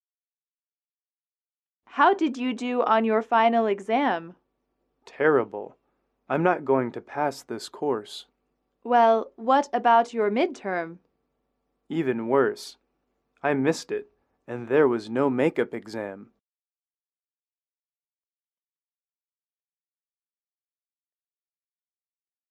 英语口语情景短对话18-4：糟糕的考试（MP3）